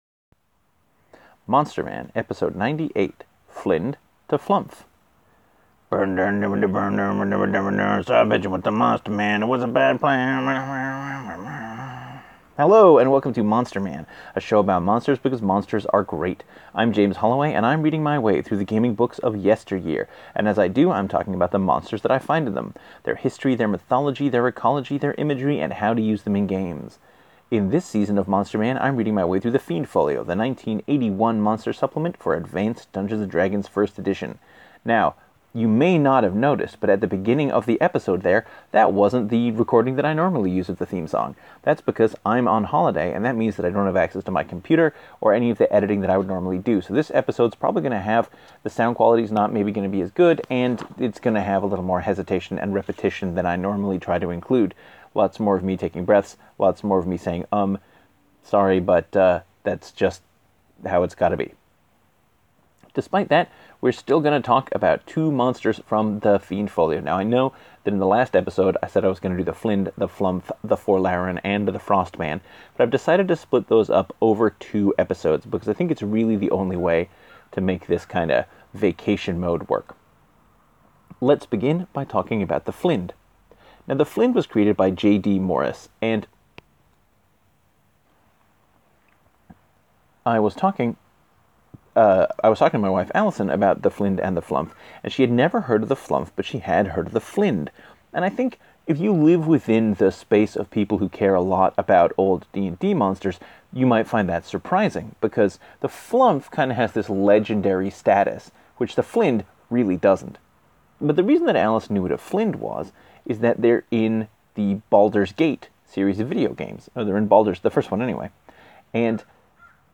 Really enjoyed your vocal stylings got the theme song.